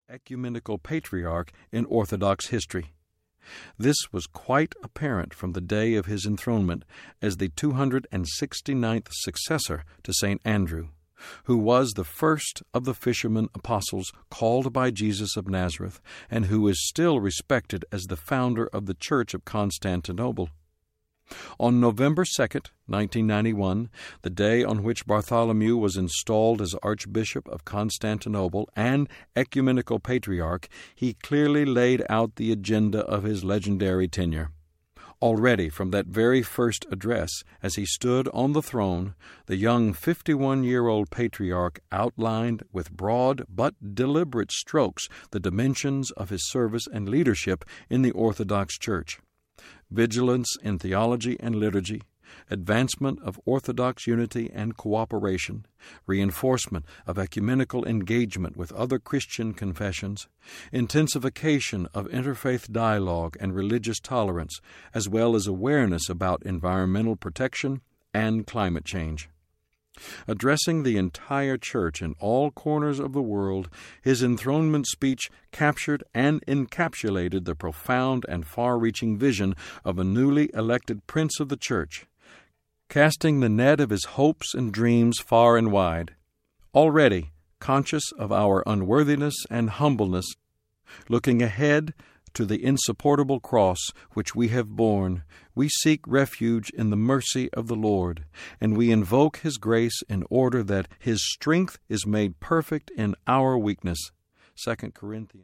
Bartholomew Audiobook
Narrator
8.32 Hrs. – Unabridged